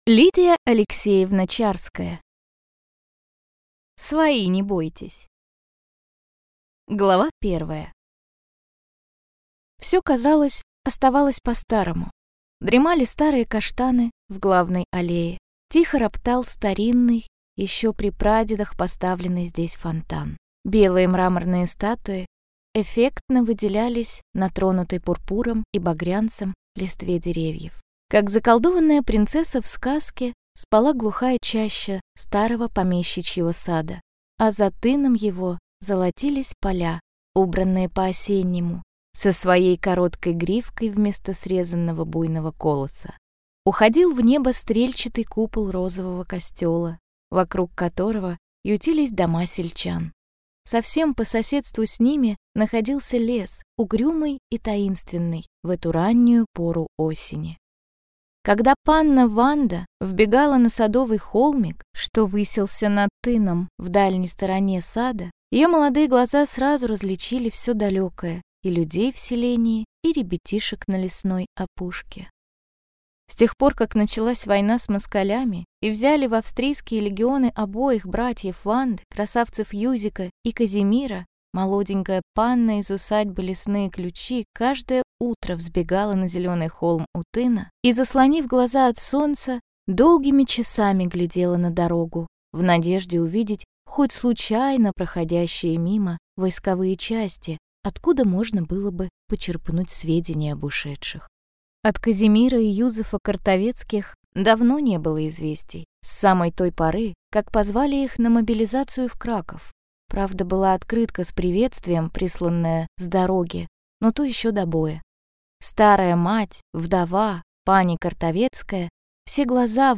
Аудиокнига Свои, не бойтесь!